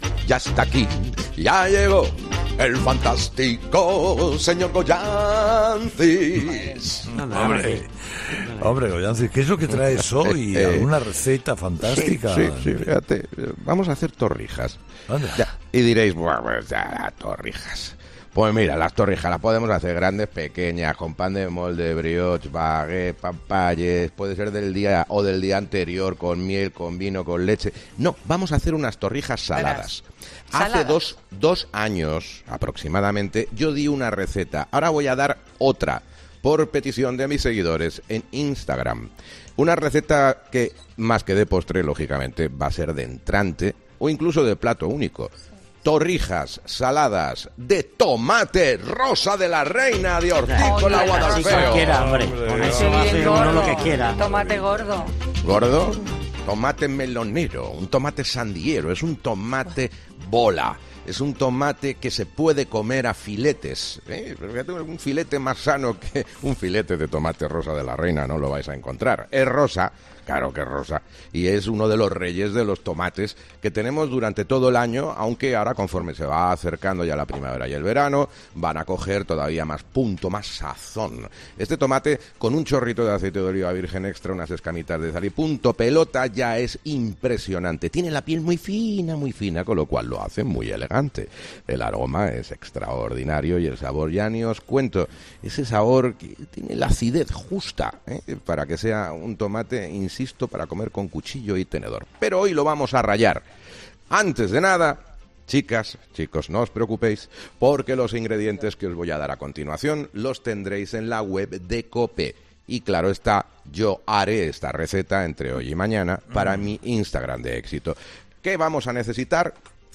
Durante la explicación de esta receta, el equipo completo de 'Herrera en COPE' no podía evitar dar su opinión durante la mención de los ingredientes o en algunos de los pasos de la receta.